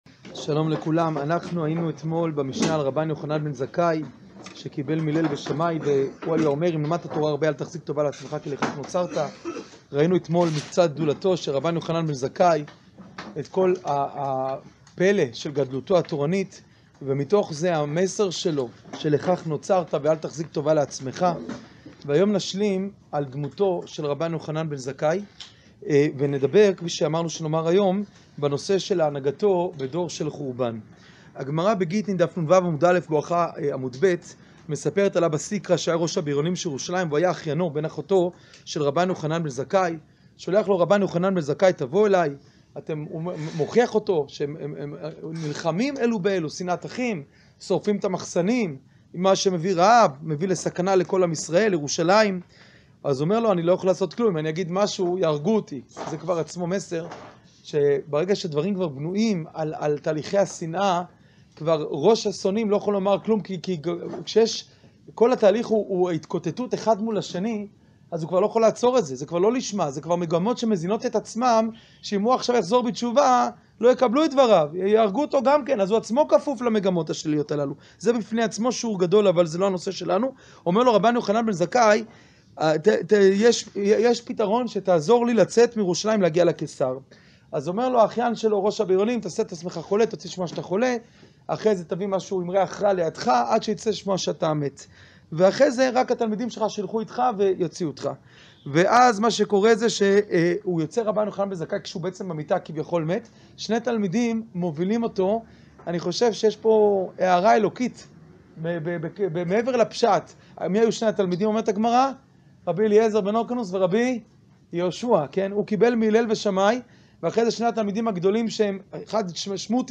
שיעור פרק ב משנה ח